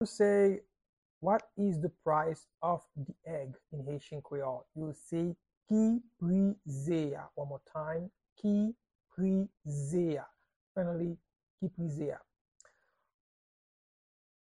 Pronunciation and Transcript:
How-to-say-What-is-the-price-of-the-egg-in-Haitian-Creole-–-Ki-pri-ze-a-pronunciation-by-a-Haitian-speaker.mp3